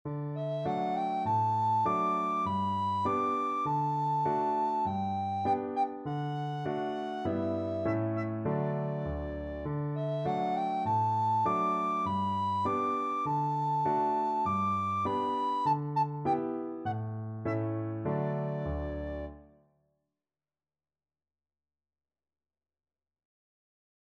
Childrens Childrens Soprano
Arrangement for solo Recorder
Moderato
4/4 (View more 4/4 Music)